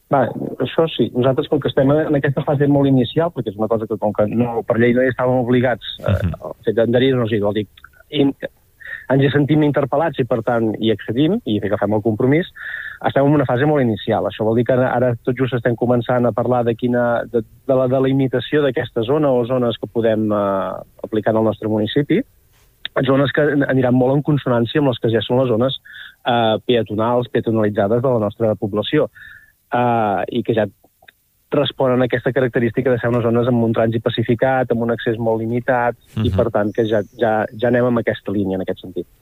Per això ens va visitar Marc Heras, regidor de Medi Ambient de Palafrugell, al Supermatí.